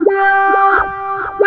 VOX FX 5  -L.wav